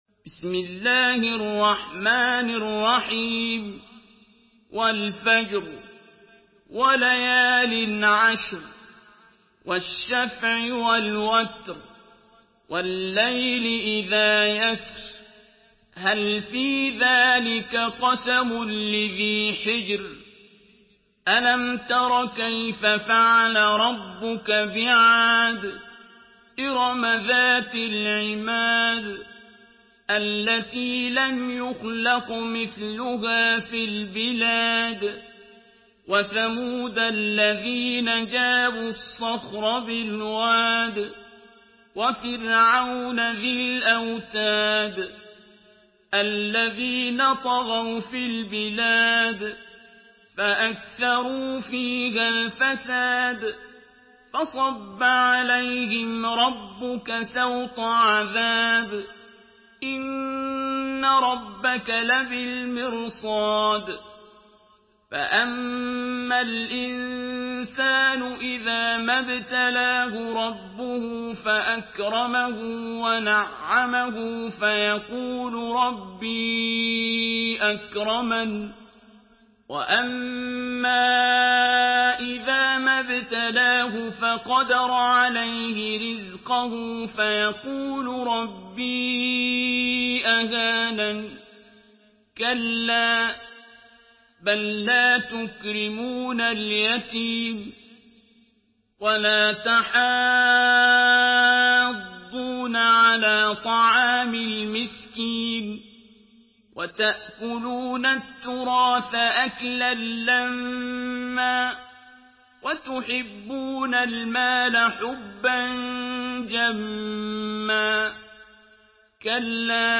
سورة الفجر MP3 بصوت عبد الباسط عبد الصمد برواية حفص
مرتل حفص عن عاصم